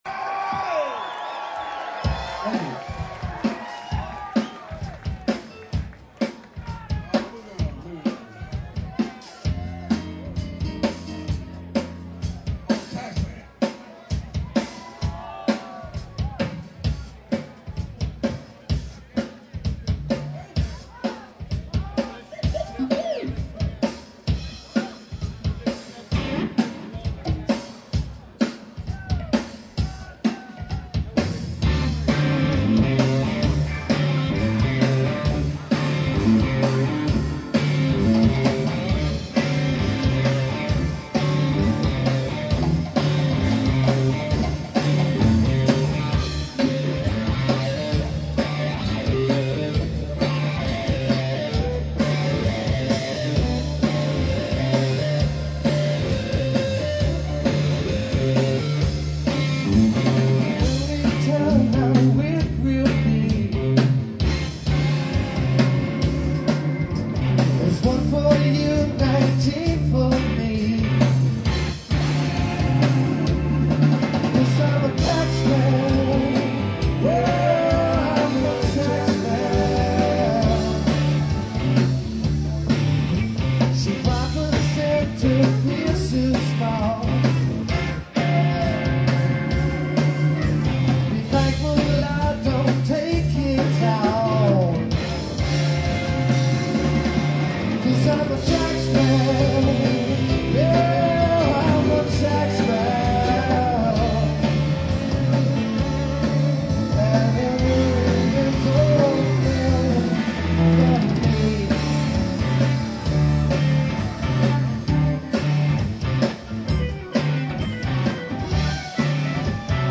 they cover Pink Floyd songs in a blues style.